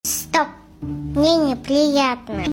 stop mne ne priiatno Meme Sound Effect